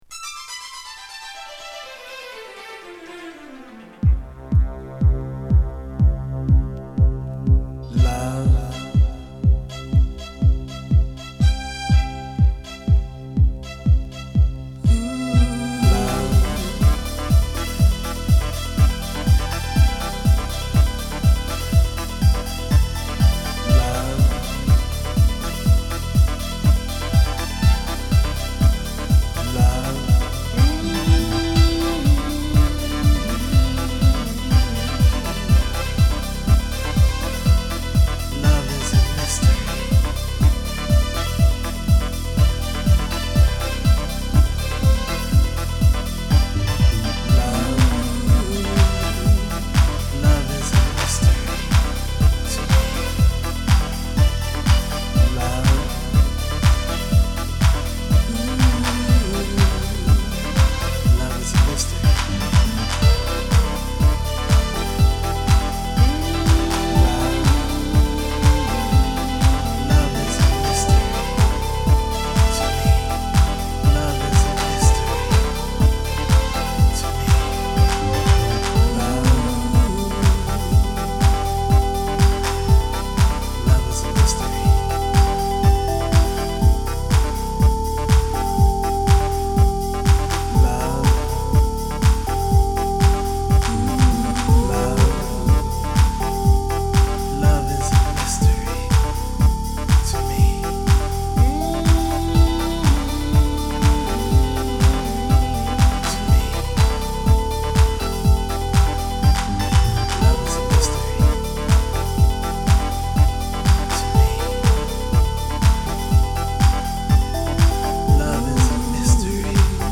Deep House